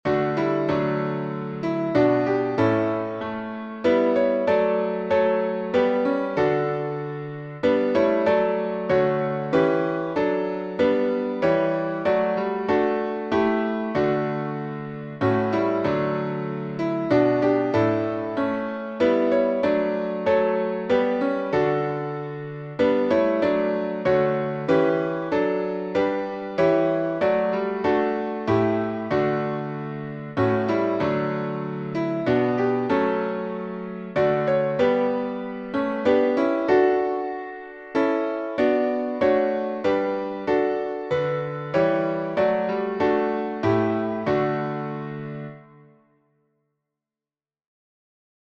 Traditional English carol as early as 13th Century Tune: THE FIRST NOEL, Traditional melody from W. Sandy's